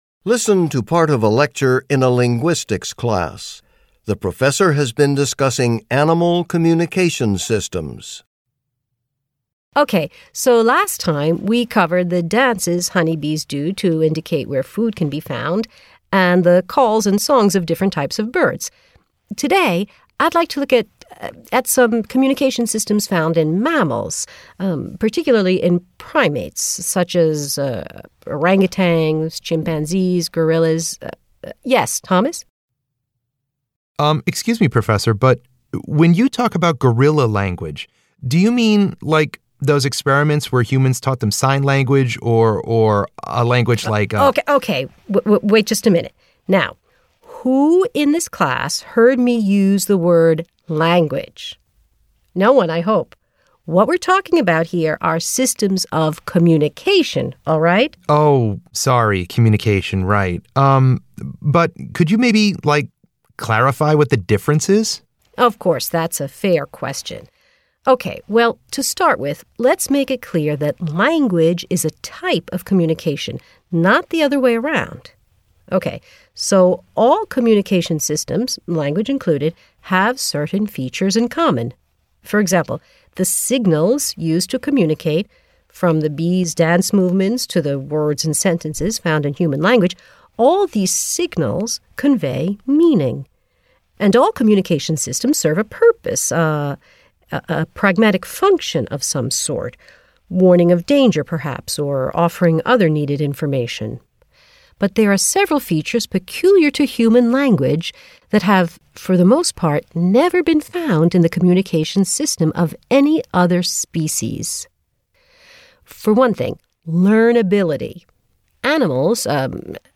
Directions: This section measures your ability to understand conversations and lectures in English.